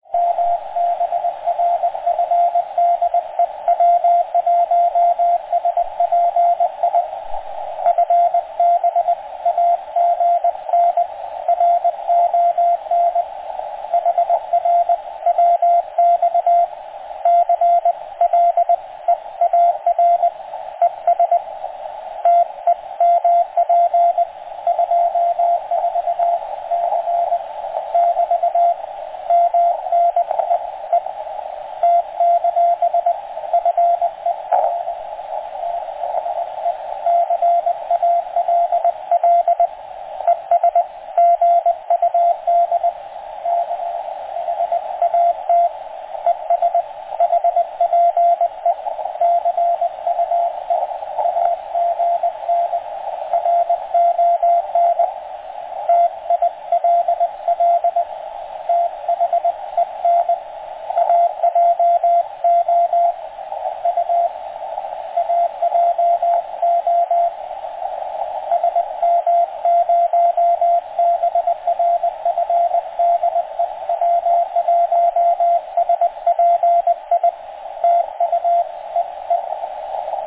He was using an MFJ loop, horizontally mounted, on the balcony on second floor and 100W.